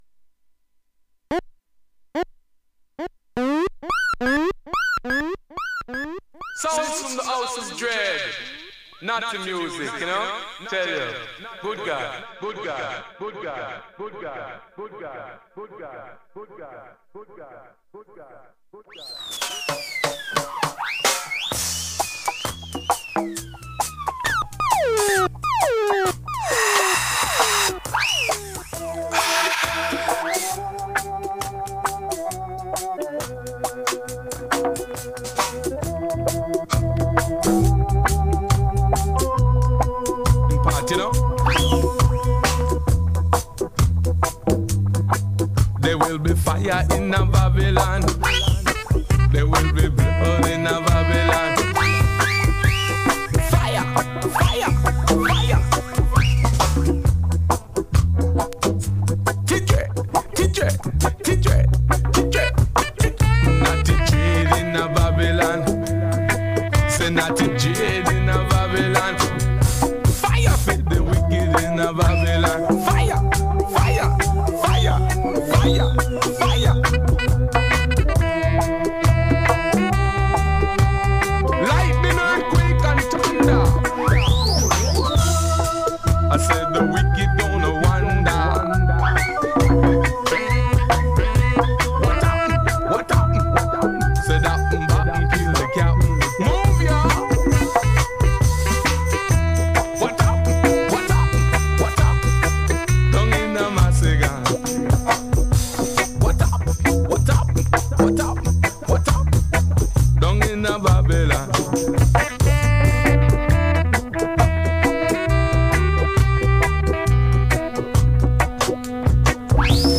Fulljoy a set of classic tunes out of my longplayercrates.